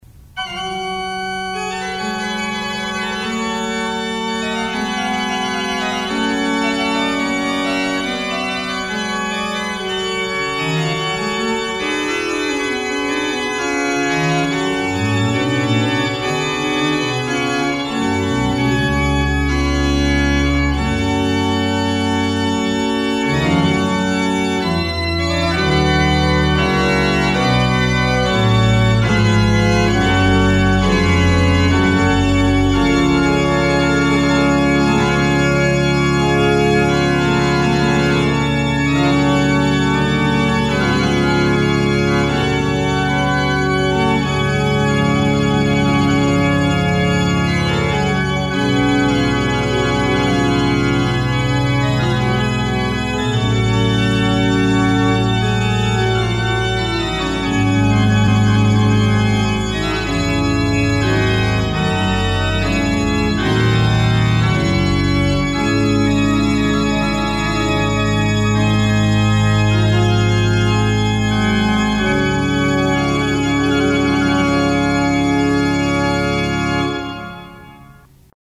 (Tempérament de Lambert Chaumont).